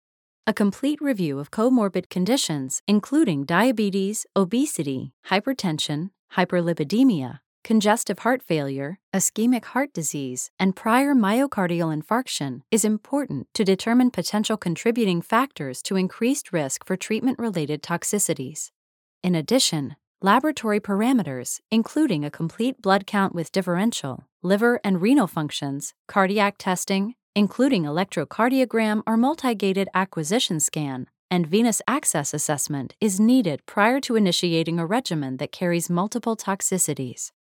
standard us
medical narration